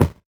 CS_VocoBitB_Hit-09.wav